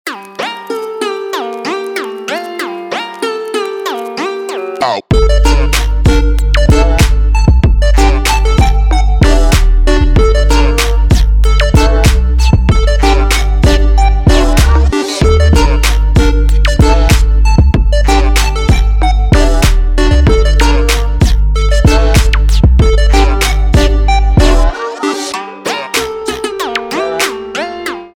Electronic
без слов
Trap
Bass
Интересная Трэп мелодия без слов